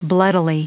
Prononciation du mot : bloodily
bloodily.wav